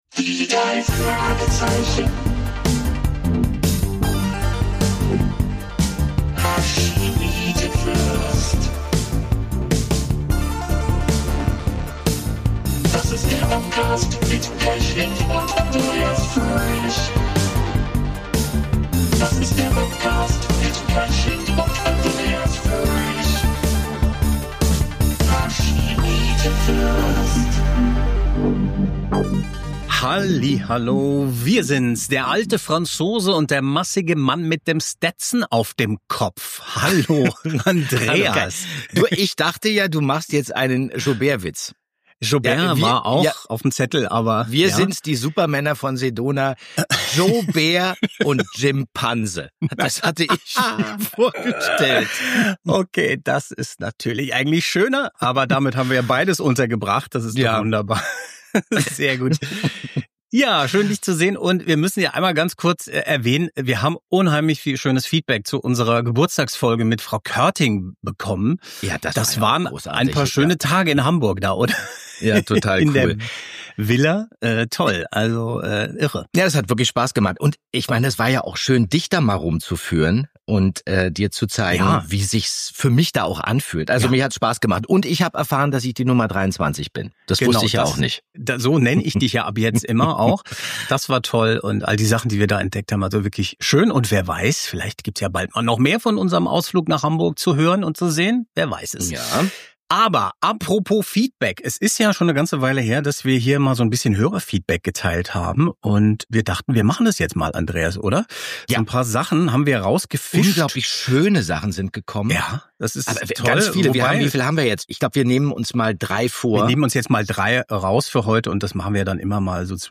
Umfassend und in chronologischer Reihenfolge konzentrieren sich die Bobcast-Macher auf die Anfänge der Serie; immer mit O-Tönen und Musik aus den Hörspielen sowie einem Blick ins Original-Manuskript.